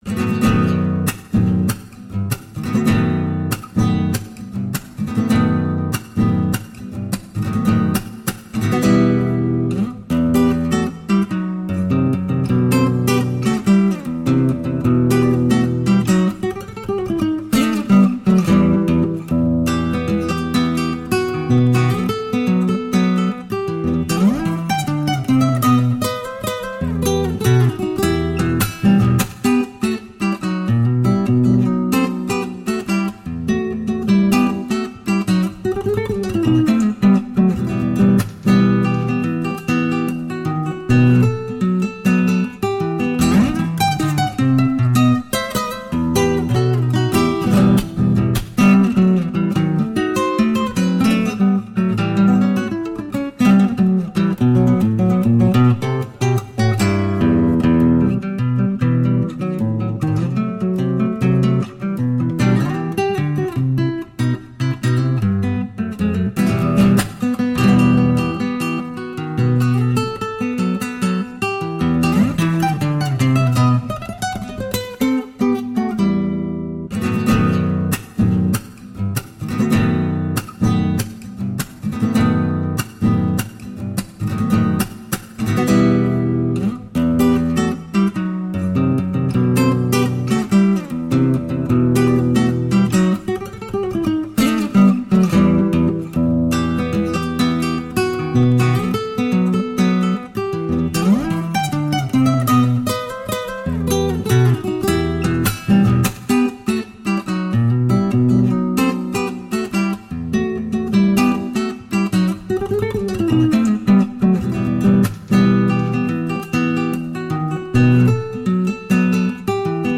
Chacarera